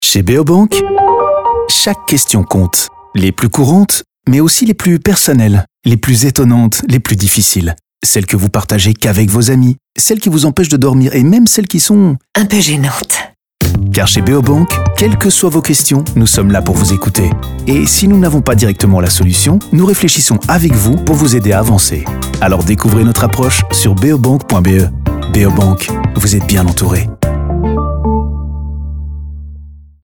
Sound studio: Cobra
Beobank B2a Radio FR.mp3